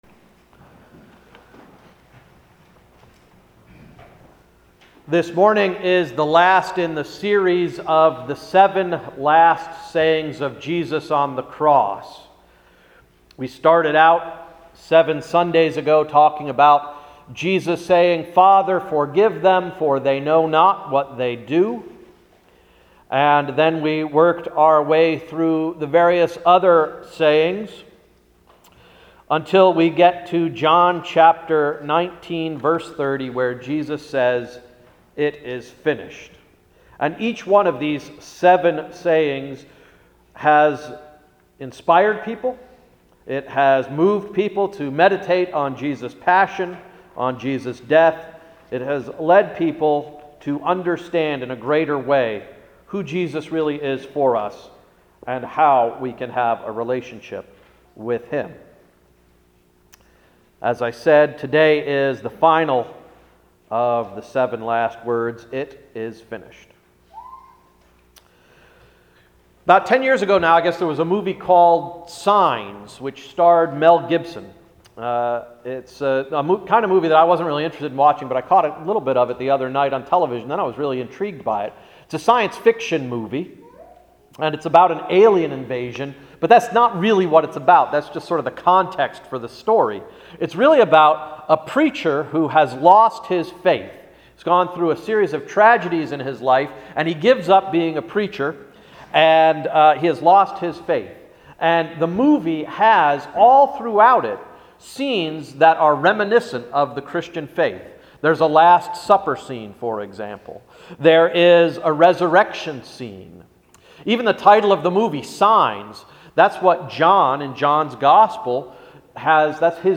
Sermon of March 24, 2013–“It is Finished” – Emmanuel Reformed Church of the United Church of Christ